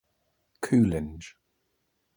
Captions English Pronunciation